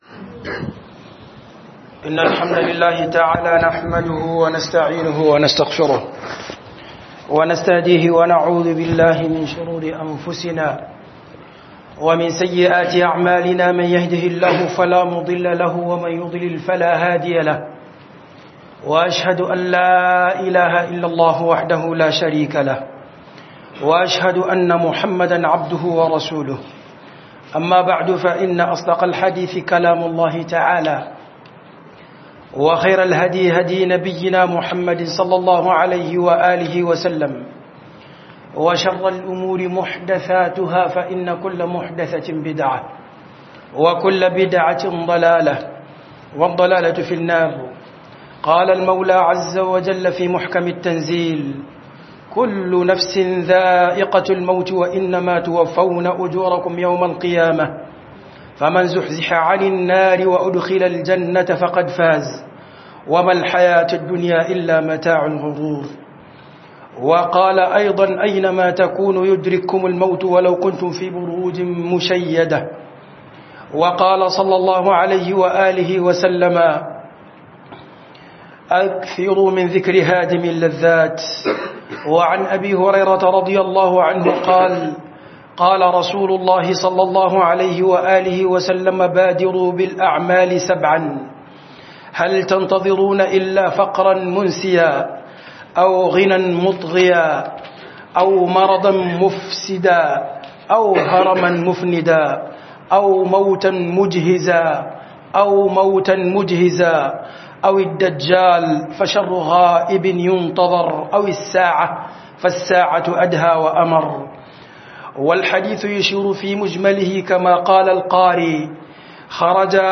MUTUWA GASKIYA - KHUDUBA